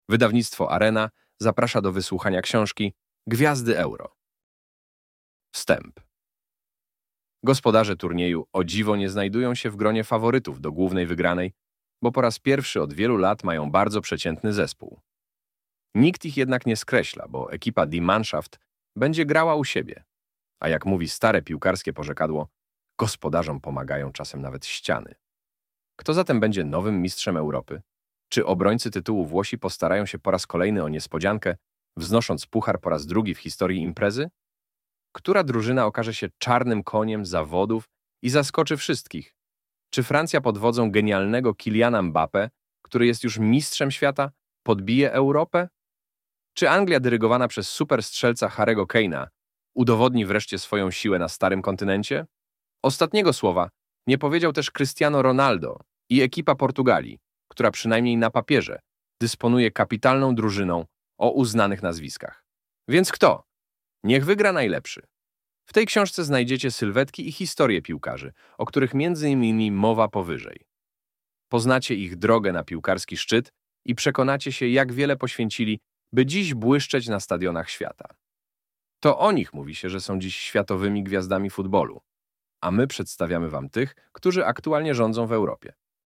Gwiazdy Euro MP3 - Mirosław Winiarczyk - audiobook